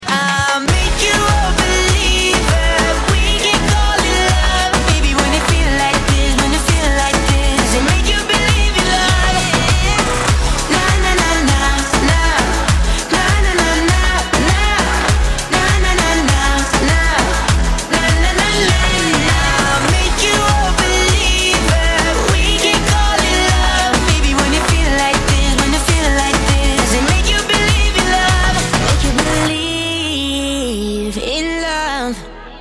• Качество: 128, Stereo
поп
мужской вокал
dance